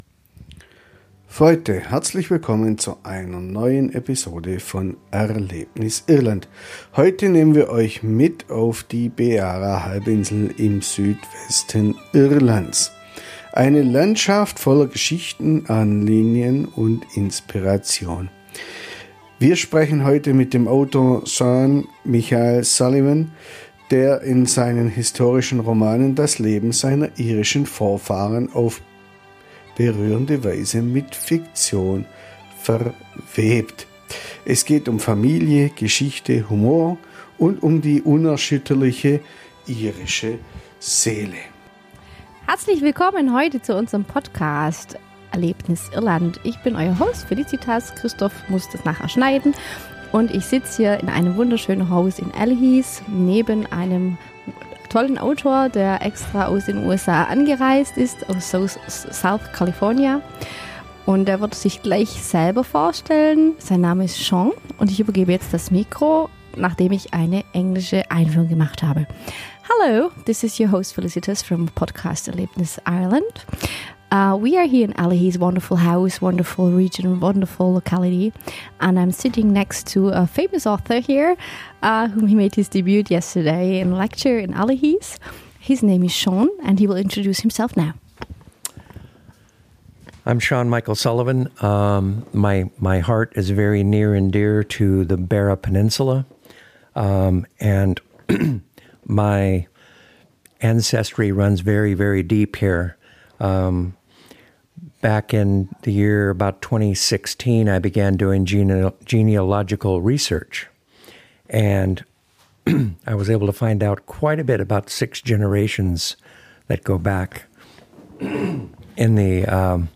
Ein Gespräch über Erinnerung, Heimat und die Kraft des Geschichtenerzählens – aufgenommen an einem besonderen Ort an Irlands Atlantikküste.
A heartfelt conversation about memory, belonging, and the art of storytelling.